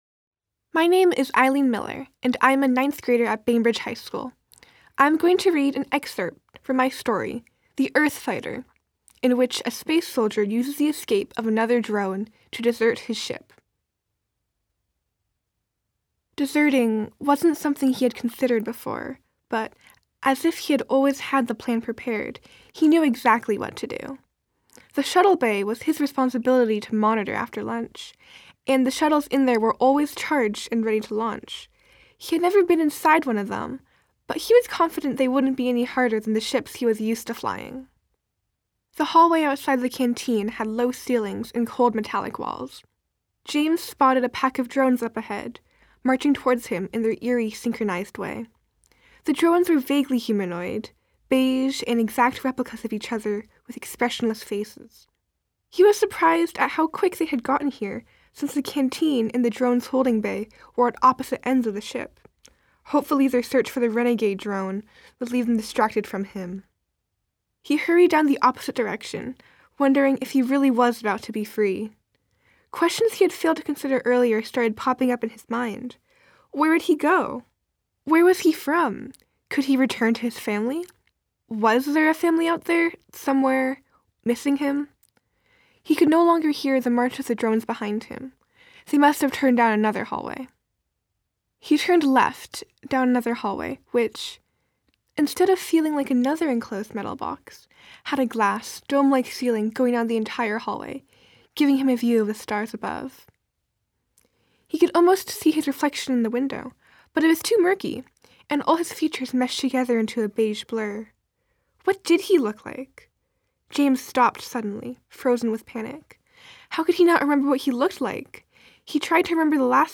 This year, MoPop and Jack Straw Cultural Center collaborated to provide winners of their annual Write Out of This World Writing Contest with a unique experience: Winners participated in a writing workshop, voice workshop, and recording session at Jack Straw, resulting in a professional recording of each writer reading their work.